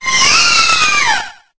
9fa56202bbdcc78ce0cd9e1d813f027bc579e33c infinitefusion-e18 / Audio / SE / Cries / ALCREMIE.ogg infinitefusion d3662c3f10 update to latest 6.0 release 2023-11-12 21:45:07 -05:00 10 KiB Raw History